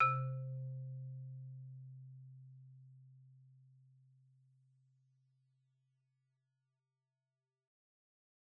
Marimba_hit_Outrigger_C2_loud_01.wav